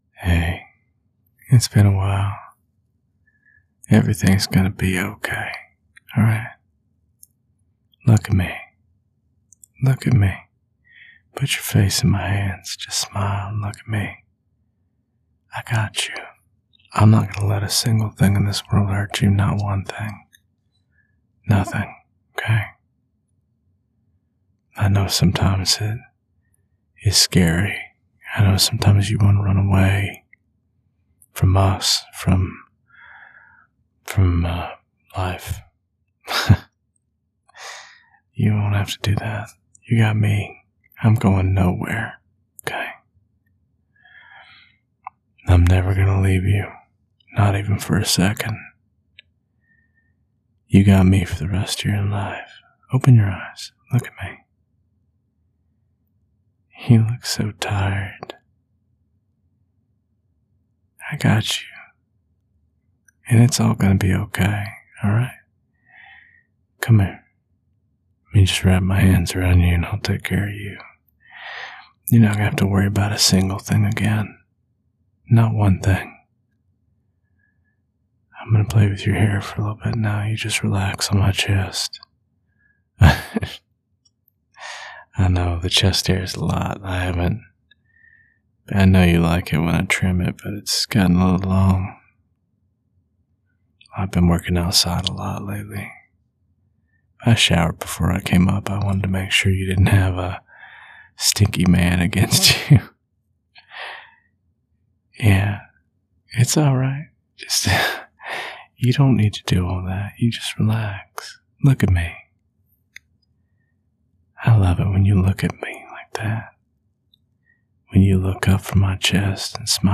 This audio is a sweet, southern accent ASMR audio.